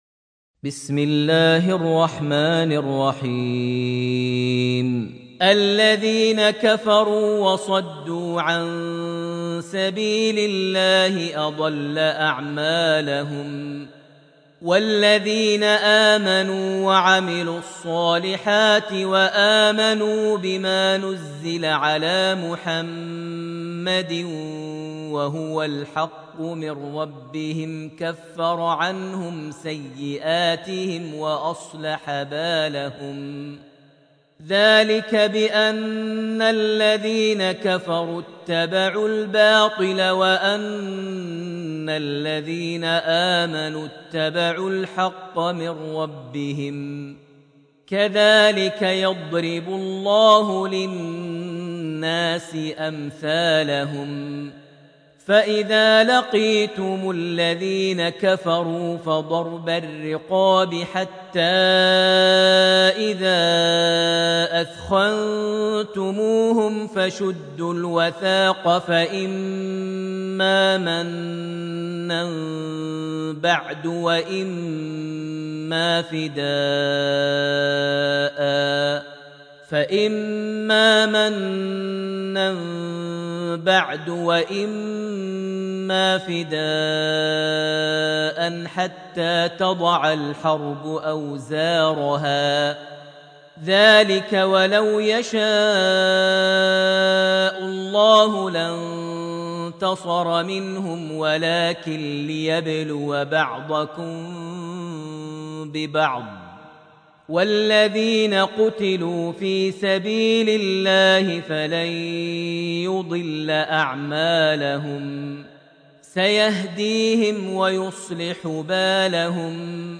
surat Muhammed > Almushaf > Mushaf - Maher Almuaiqly Recitations